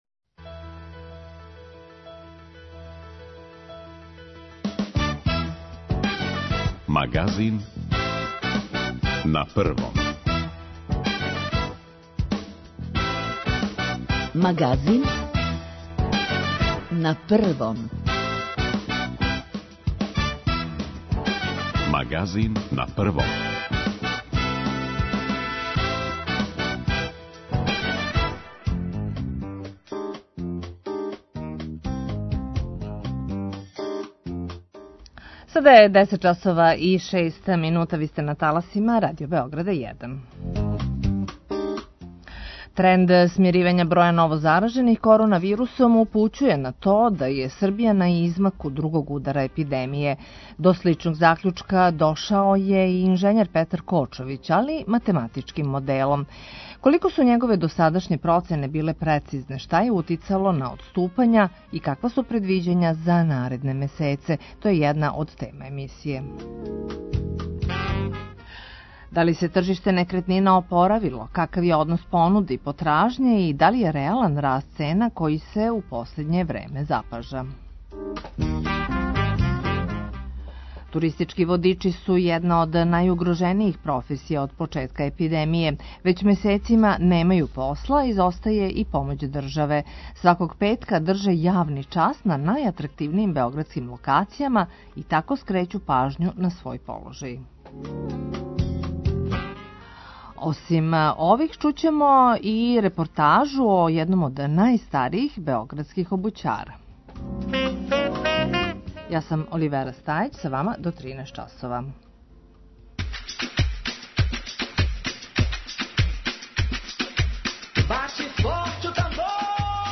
Чућете и репортажу о једном од најстаријих београдских обућара.